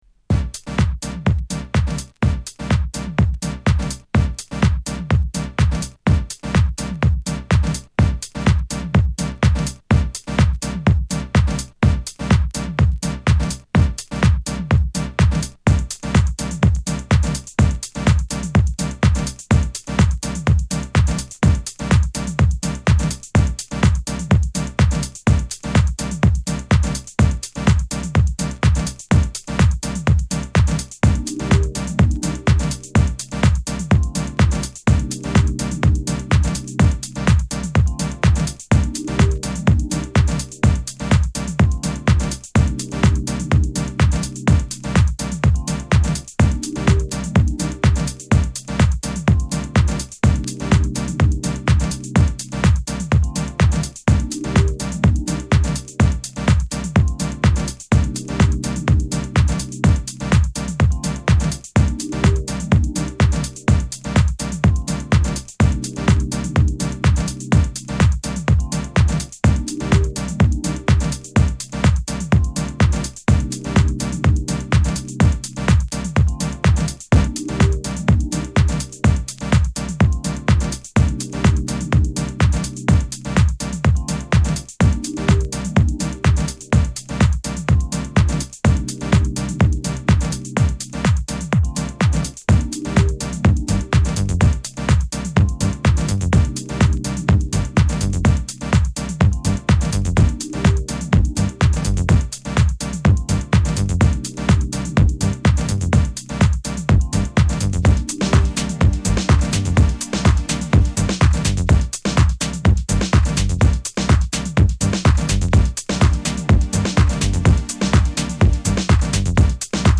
TOP > Detroit House / Techno > VARIOUS